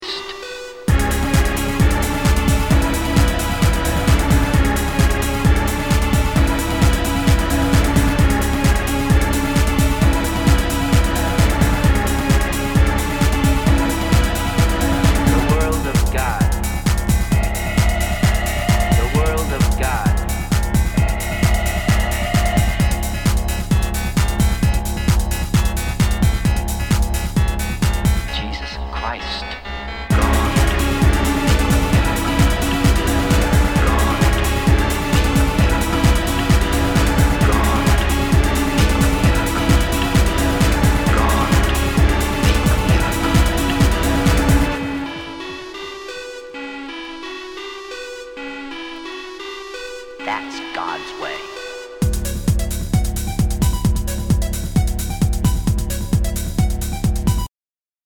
HOUSE/TECHNO/ELECTRO
ナイス！ユーロ・テクノ・クラシック！
全体にチリノイズが入ります